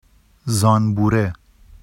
[zɑnbure] n bee